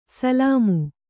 ♪ salāmu